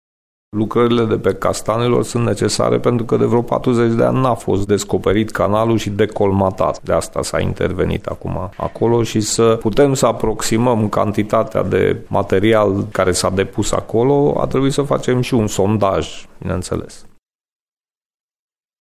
O astfel de acțiune de curățare n-a fost făcută de zeci de ani, spune viceprimarul municipiului Brașov, Barabas Laszlo: